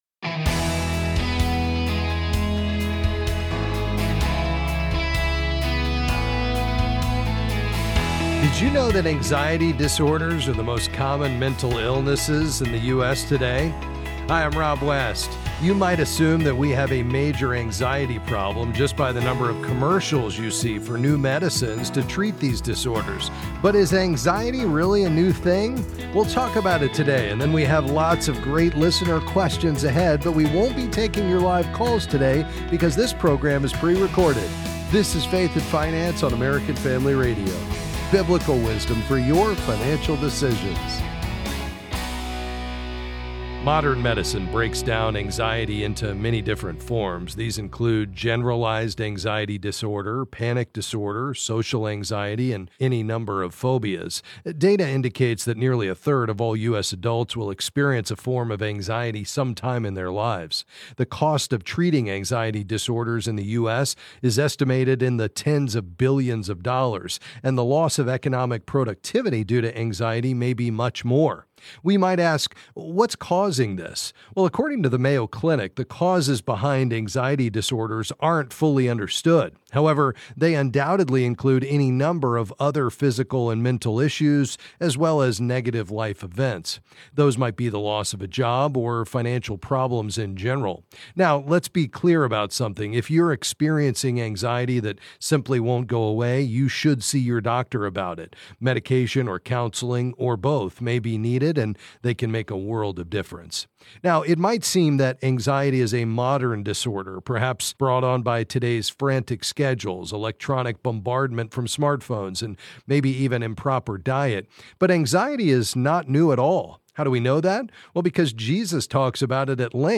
Then he answers some calls on various financial topics.